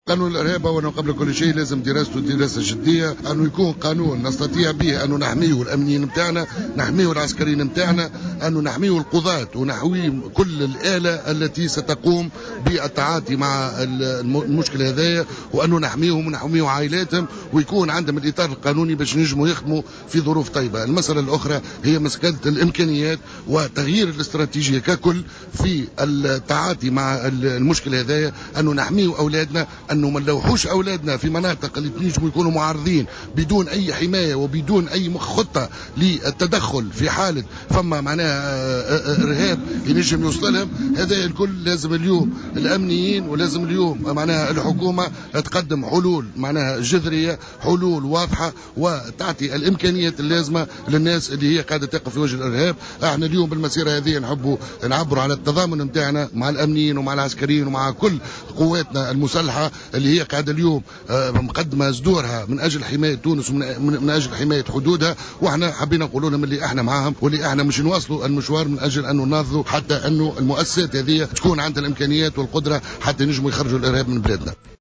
دعا القيّادي في حركة نداء تونس عبد العزيز الڨطي خلال مسيرة للتنديد بالإرهاب انتظمت اليوم السبت بشارع الحبيب بورقيبة بالعاصمة الى الالتفاف حول المؤسستيْن الامنيّة والعسكرية ومساندة جهودها في التصدي للإرهاب، مؤكدا على ضرورة توفيّر الأطر والإمكانيات اللازمة لأعوان الأمن والجيش الوطنين ليكونوا قادرين على مواجهة هذه الآفة التي أصبحت تهدد تونس.